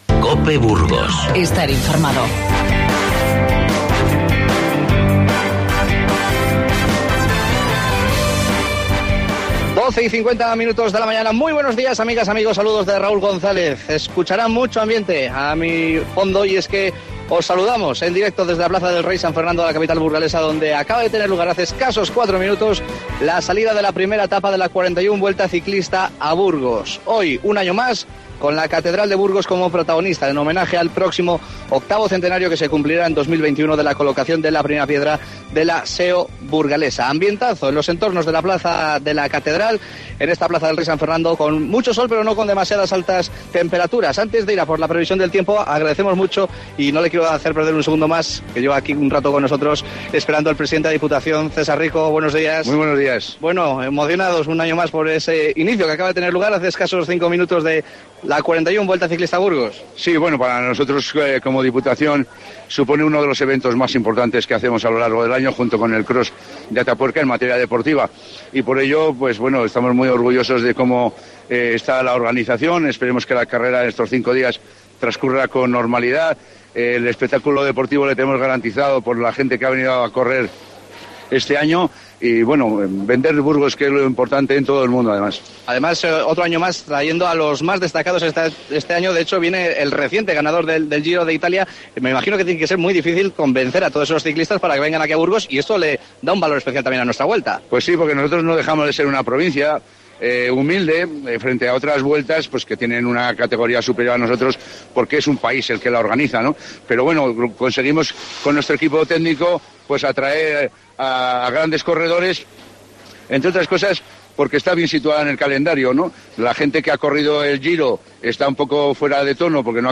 AUDIO: Desde la Plaza del Rey San Fernando, te contamos en directo el inicio de la Vuelta Ciclista a Burgos 2019.